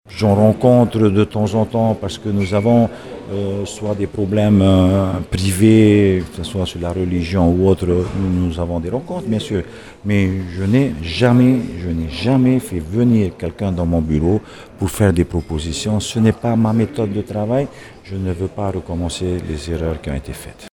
Le président Edouard Fritch a tenu une conférence de presse jeudi après-midi pour annoncer la démission de ses deux ministres, René Temeharo et Frédéric Riveta.